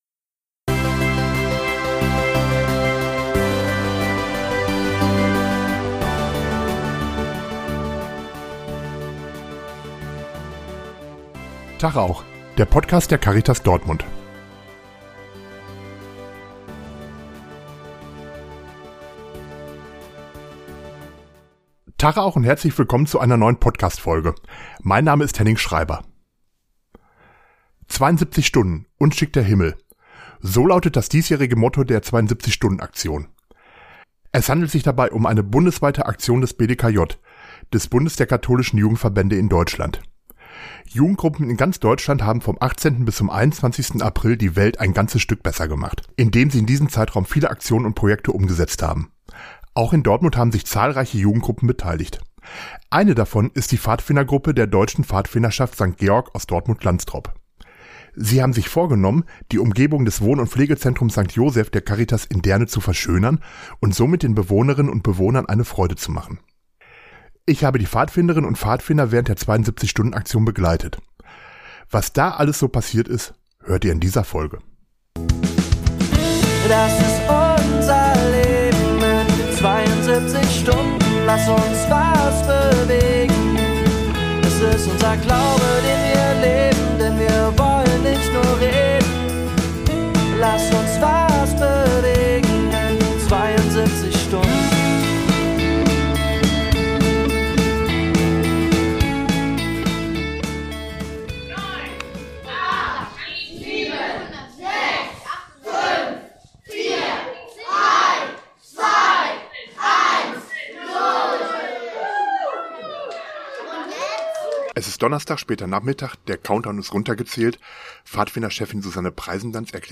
Wir haben die Pfadfinderinnen und Pfadfinder während der 72-Stunden-Aktion begleitet.